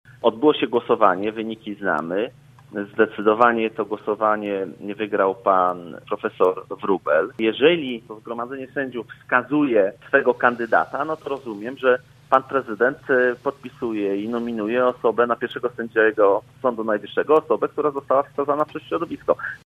Tę decyzję w programie „Rozmowa Punkt 9” skrytykował przewodniczący lubuskich struktur PO, Waldemar Sługocki: